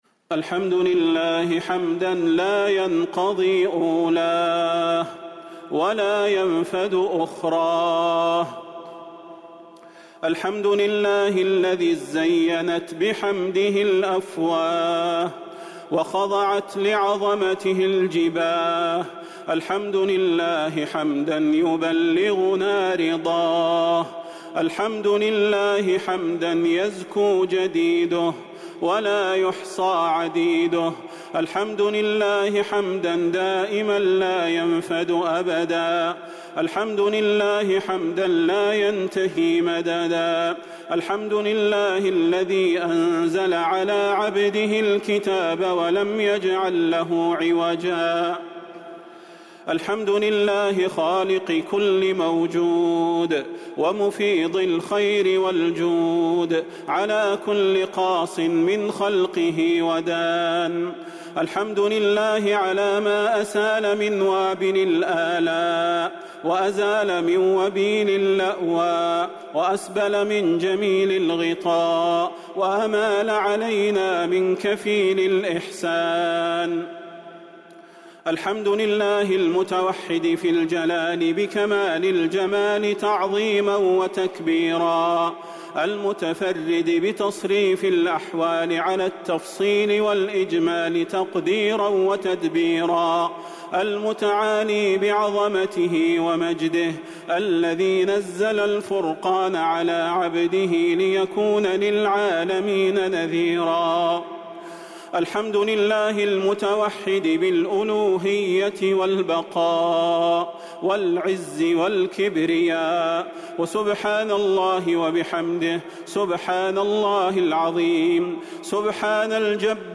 دعاء ختم القرآن ليلة 29 رمضان 1444هـ | Dua for the night of 29 Ramadan 1444H > تراويح الحرم النبوي عام 1444 🕌 > التراويح - تلاوات الحرمين